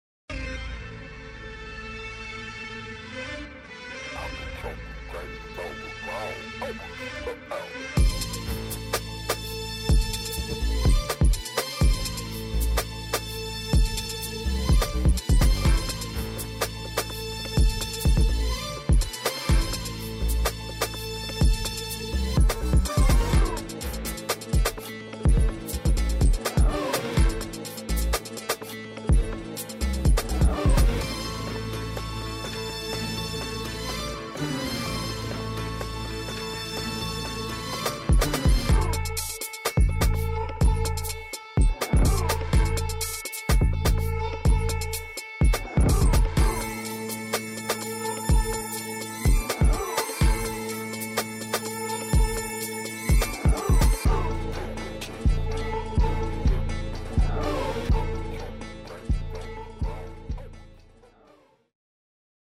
未来感十足的声音设计与有机乐器结合，160个精心策划的音色，包括10个套件和160个循环
感受定义90年代末和00年代初嘻哈、流行和R&B的独特节奏、简约编曲、未来感声音和有机乐器编制
音色展示